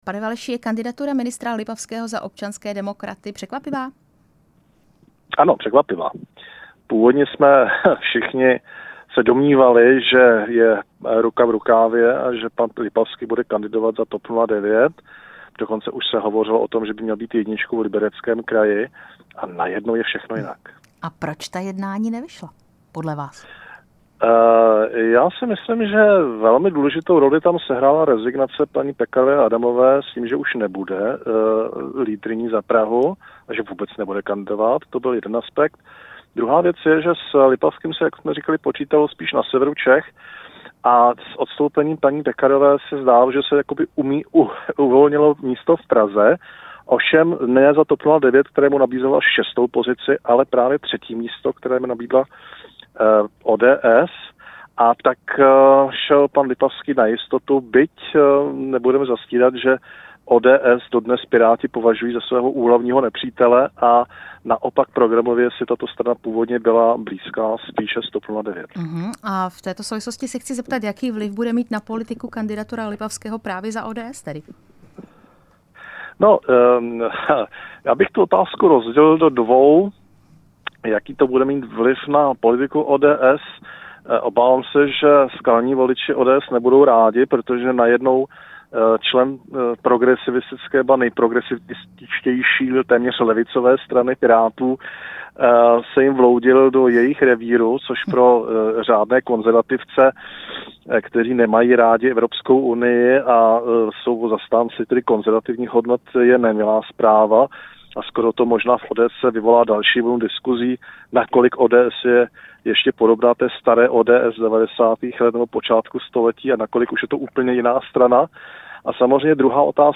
Rozhovor s politologem